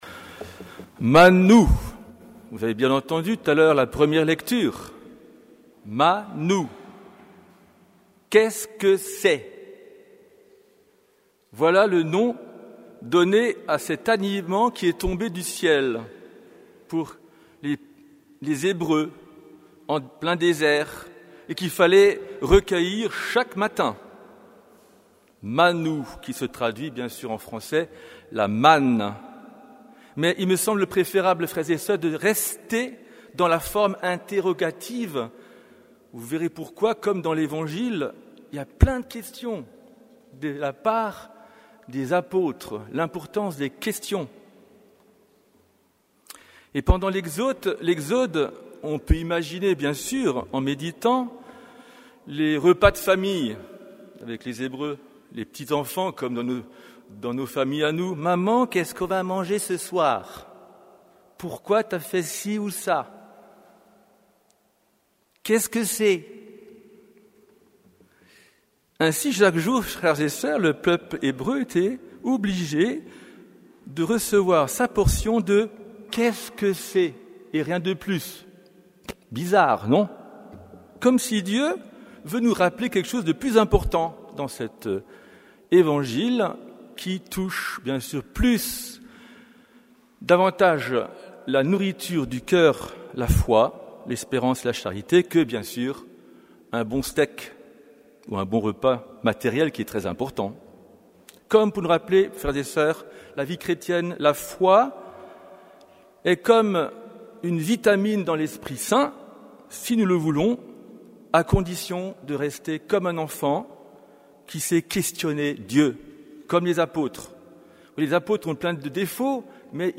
Homélie du 18e dimanche du Temps Ordinaire